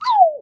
gui-in.ogg